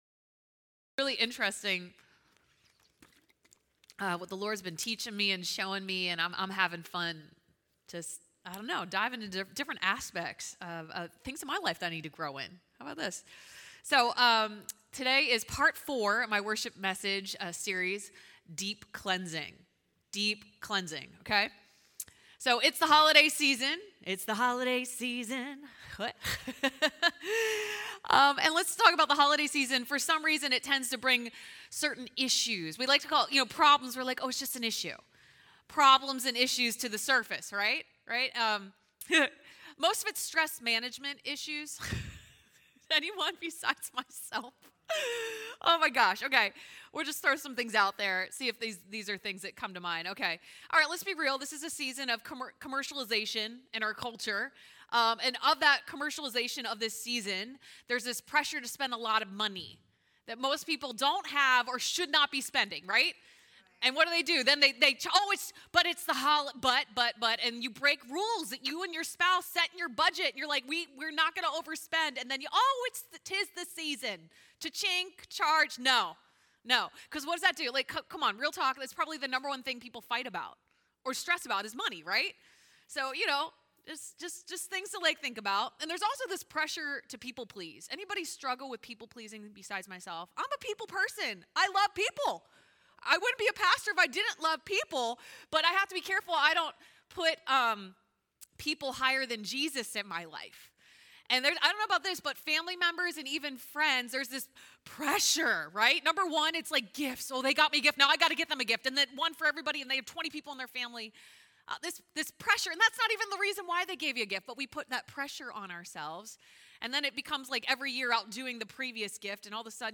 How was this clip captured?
Service Sunday AM Service